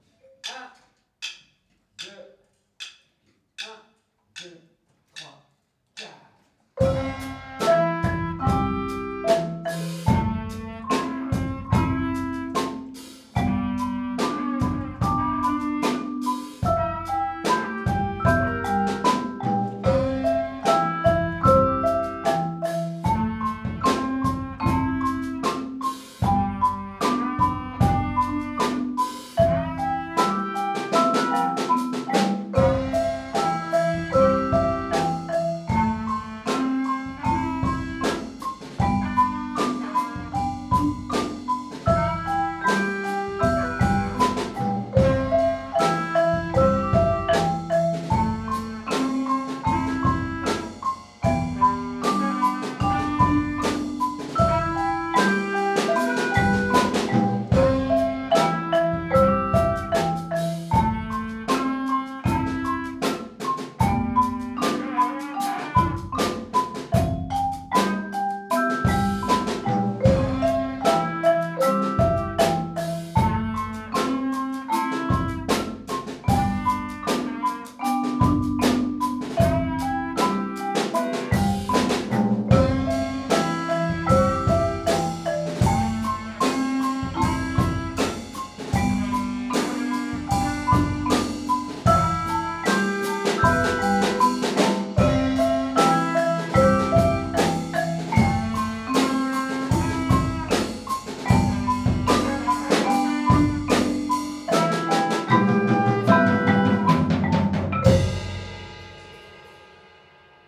🎼 Grille d'accords que vous avez choisi sur votre compo
L'accompagnement audio de votre compo